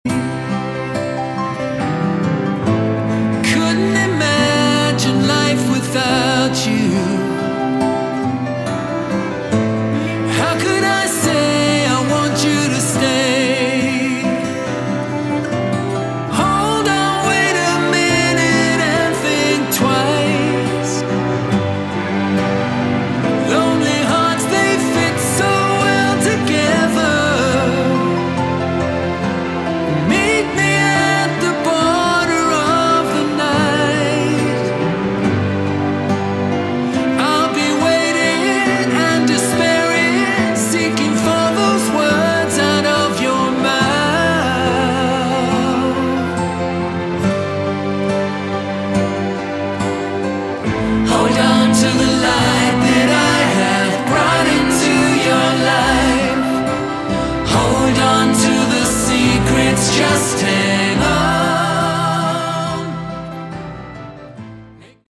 Category: Hard Rock
Guitar, Bass, Keyboards
Drums, Percussion
Lead Vocals